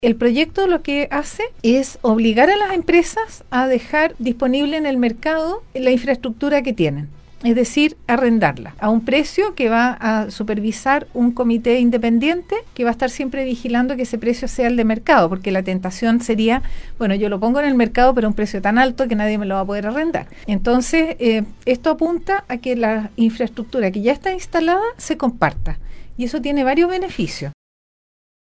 La Ministra de Transporte y Telecomunicaciones, Gloria Hutt, estuvo de visita en la capital regional donde cumplió con una apretada agenda, la cual permitió visitar las instalaciones de Nostálgica, donde se refirió al proyecto que ampliará la cobertura de telefonía e Internet en la región de Atacama.